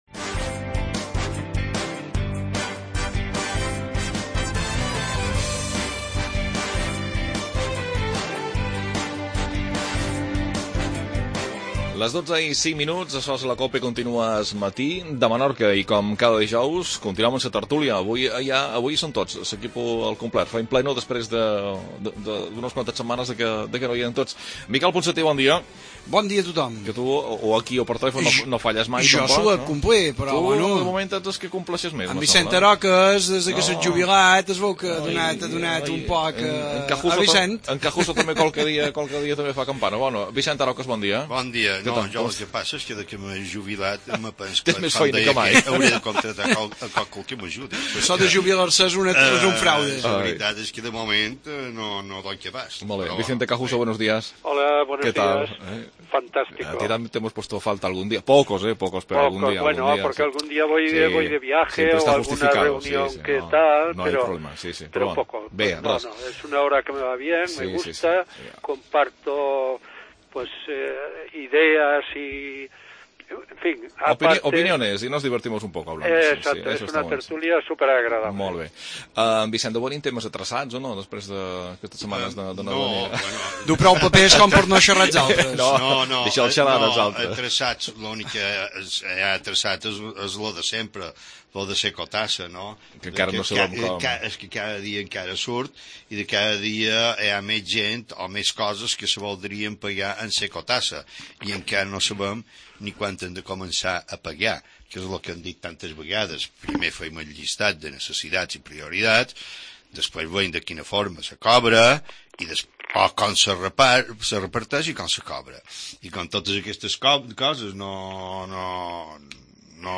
Tertulia.